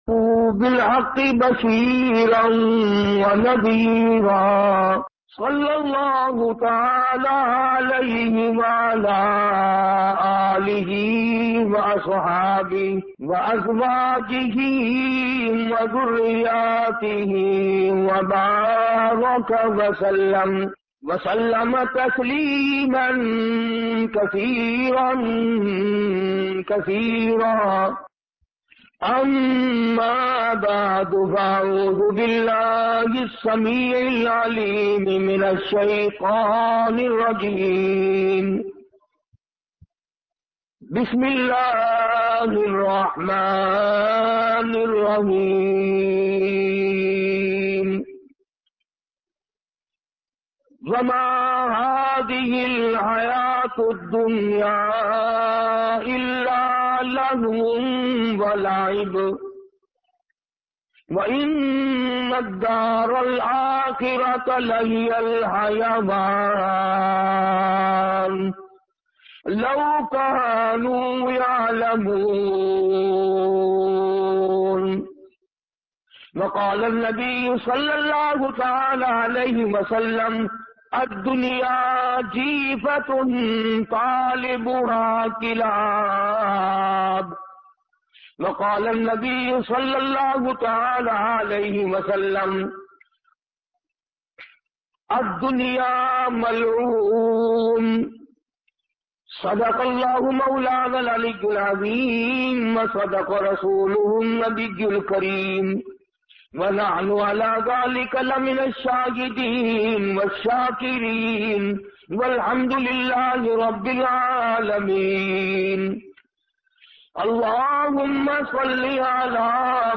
Duniya Ki Haqeeqat bayan MP3